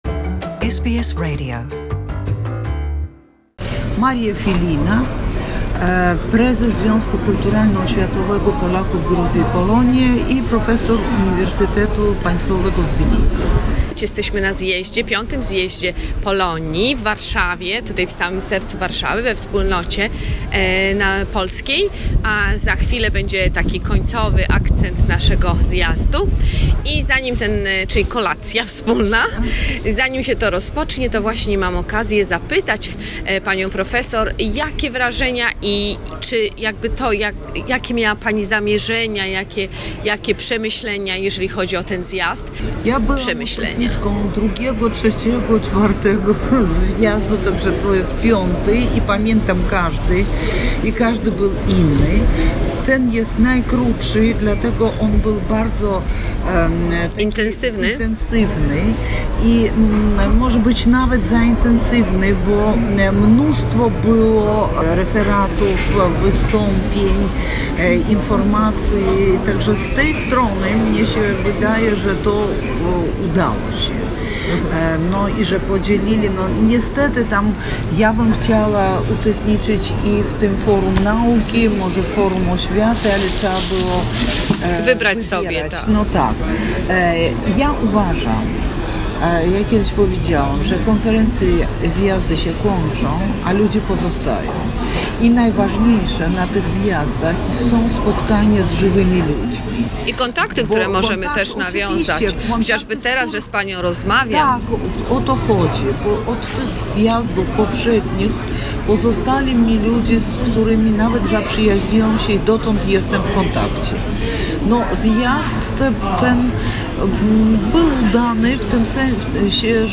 V Światowy Zjazd Polonii i Polaków z Zagranicy.